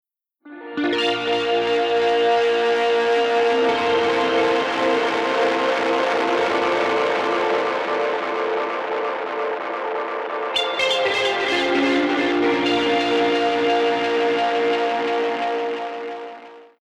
Note: In these examples, Version A is with no effect, Version B is with a lot of warbly and saturated feedback, and Version C is an all-out howling feedback effect.
Guitar-with-Galaxy-Howling_01.mp3